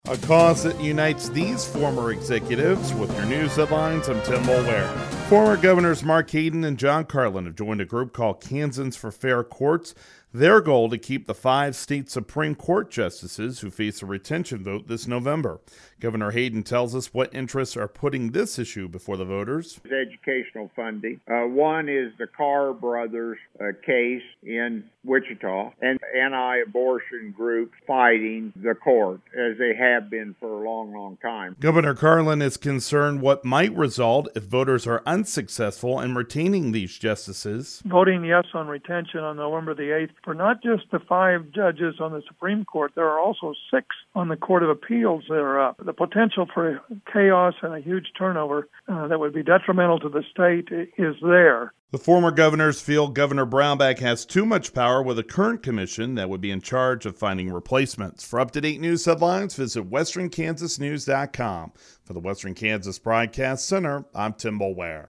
Governor Hayden tells us what interests are putting this issue before the voters.
*On-air story*